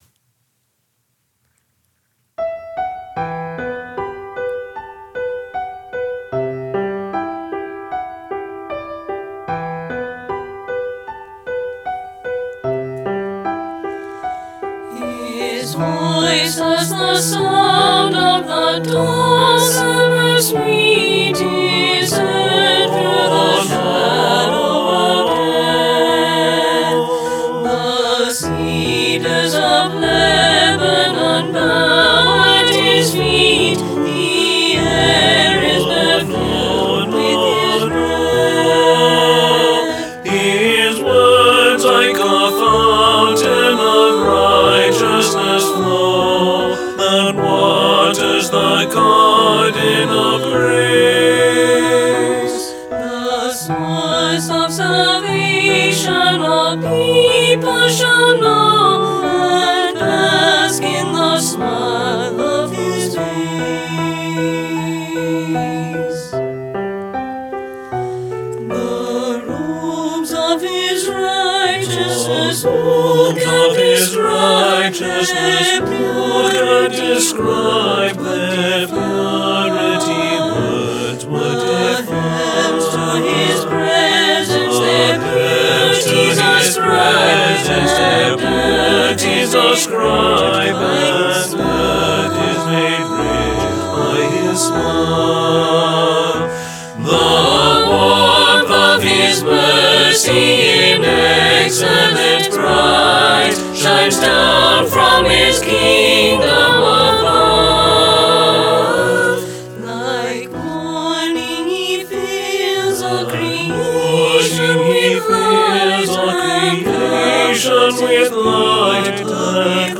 Voicing/Instrumentation: SATB